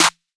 SOUTHSIDE_snare_clap.wav